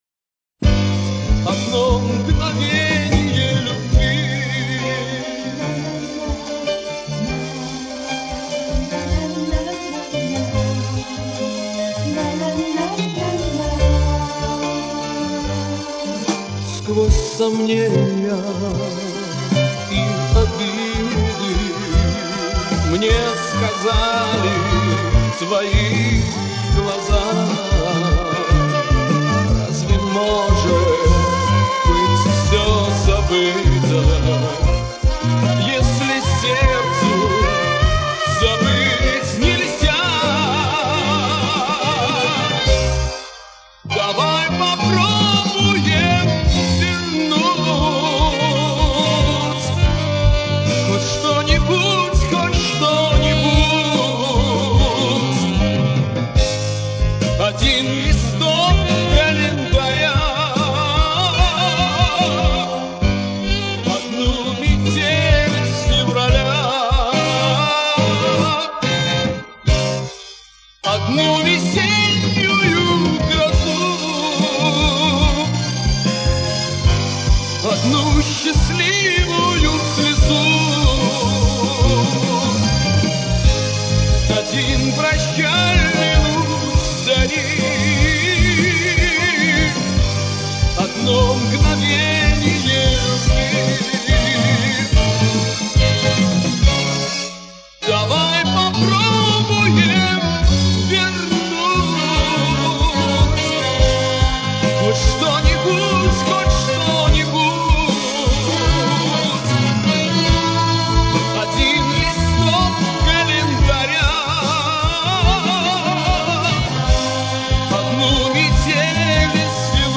Тоже концерт.